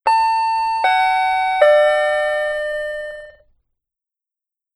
default_bell.wav